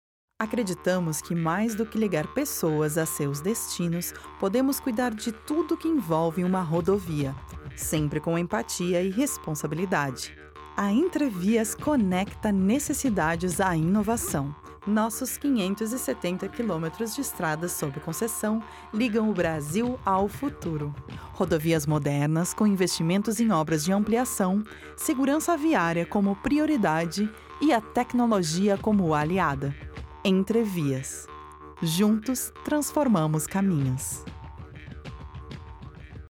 Feminino
Corporativo
Voz Padrão - Grave 00:36